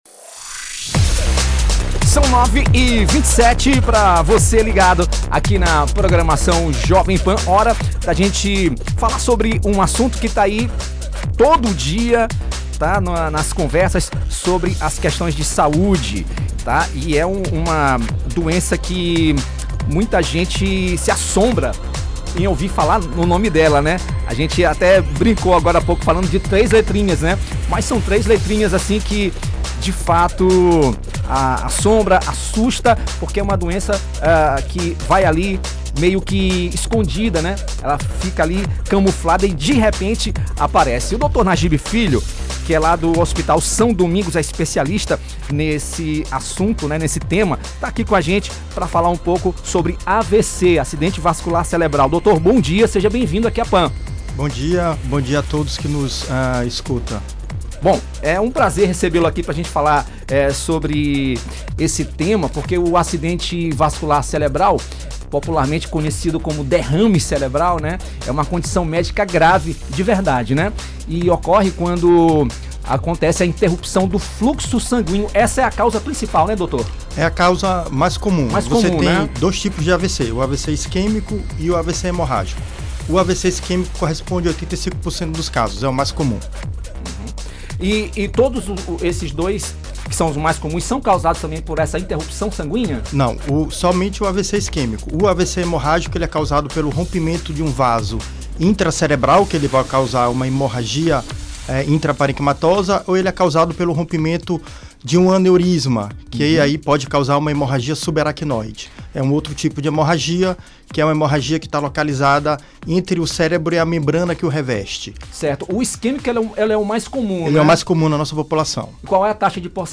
Ele esclarece pontos importantes sobre o tema e destaca avanços no diagnóstico e tratamento. Acompanhe a entrevista completa: